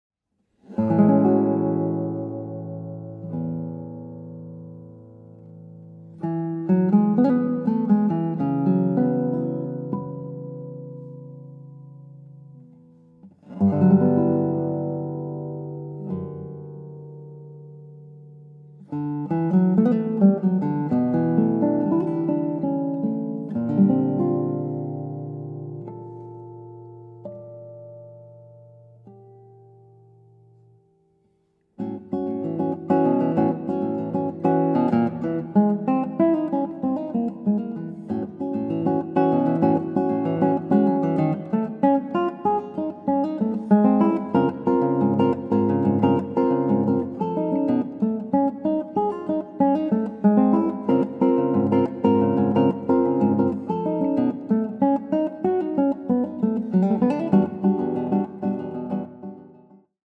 Images become sound – five world premieres for guitar